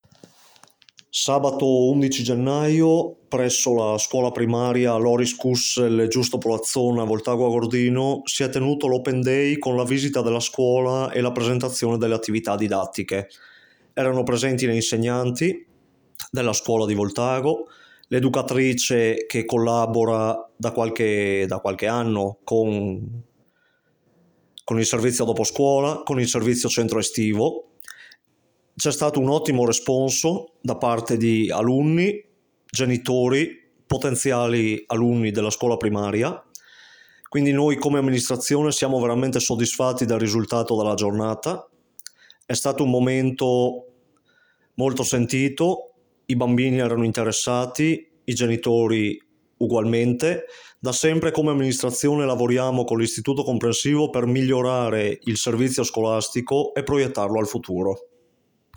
IL CONSIGLIERE CON DELEGA ALLA CULTURA ANDREA BRESSAN